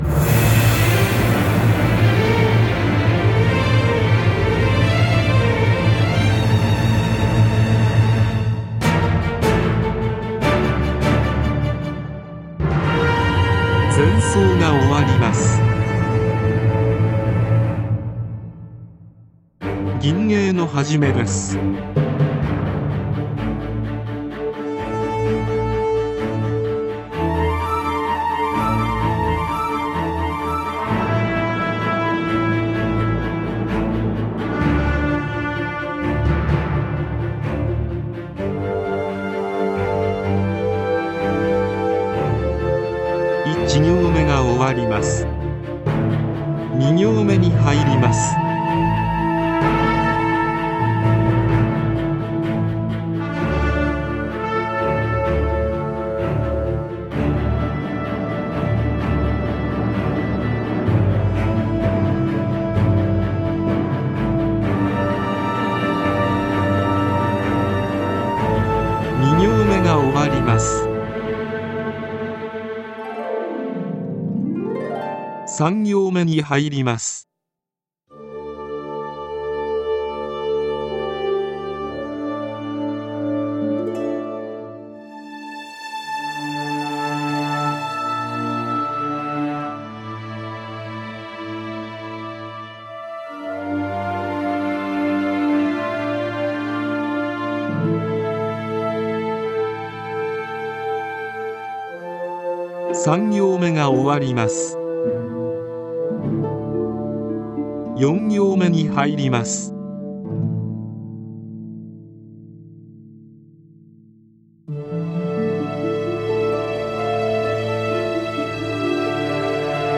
ガイド音声は目安ですので、吟法や詩によって多少前後しても大丈夫です。
伴奏曲
ガイド音声入
水2本〜4本   オーケストラ・アレンジ